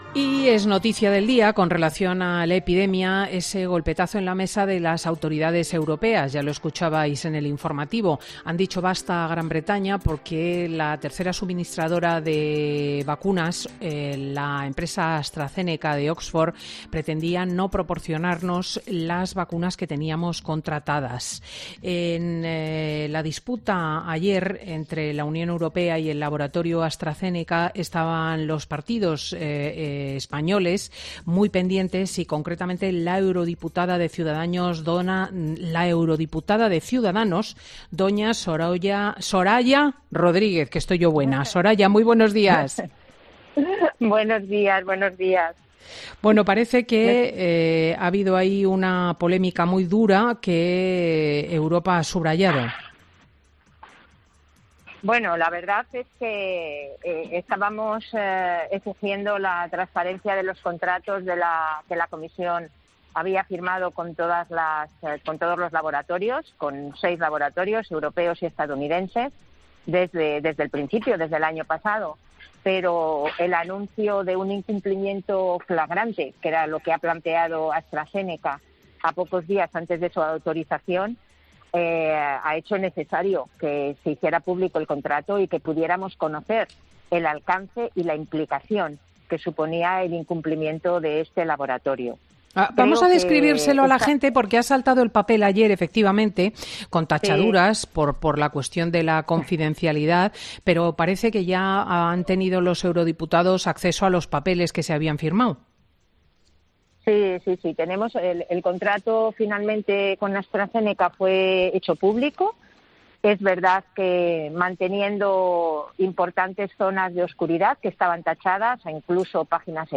La eurodiputada de Ciudadanos, Soraya Rodríguez , ha criticado este sábado en Fin de Semana de COPE que el contrato publicado este viernes entre la Unión Europea y Astrazeneca deja oculto detalles como el precio de la dosis.